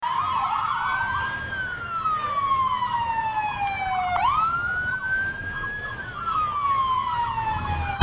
Police Siren Cinematic Hd Sound Button - Free Download & Play